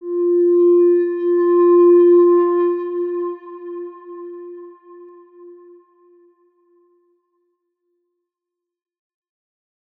X_Windwistle-F3-pp.wav